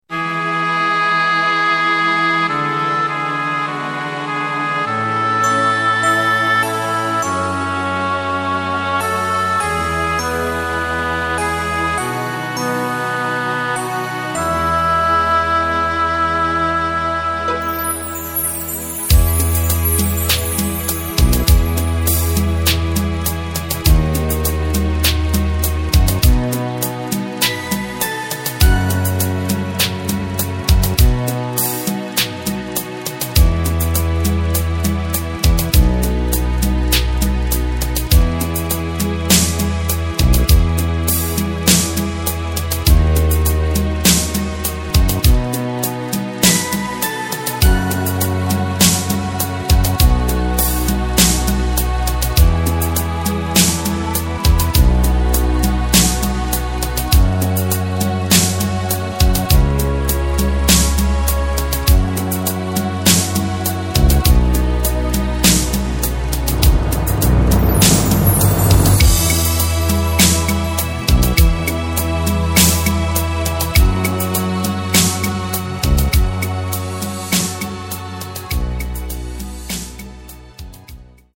Takt: 4/4 Tempo: 101.00 Tonart: E
mp3 Playback Demo